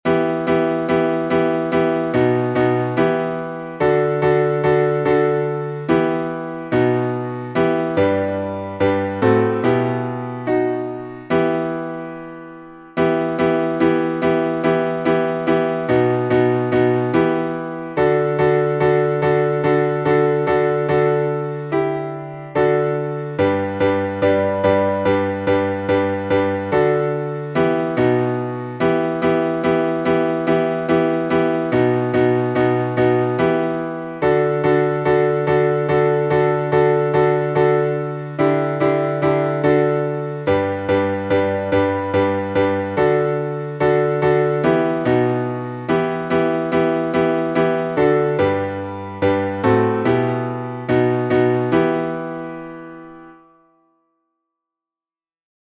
Тропари на «Бог Господь» — глас 6 — Богослужение на планшете